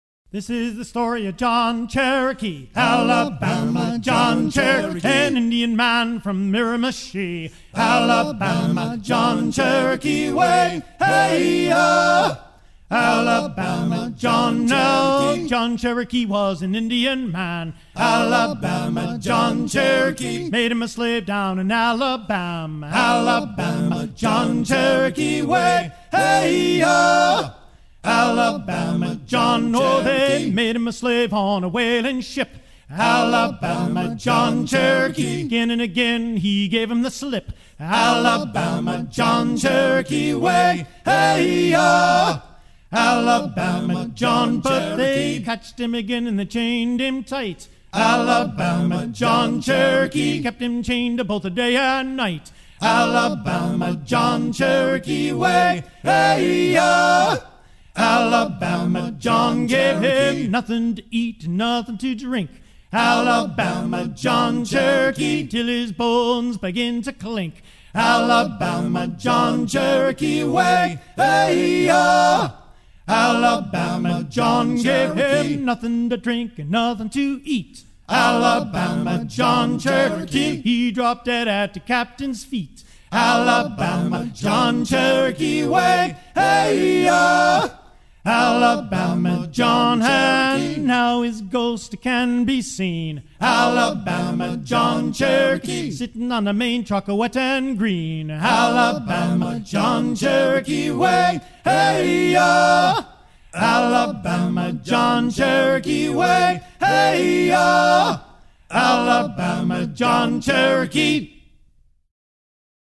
circonstance : maritimes
Genre laisse